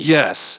synthetic-wakewords
ovos-tts-plugin-deepponies_Kanji Tatsumi_en.wav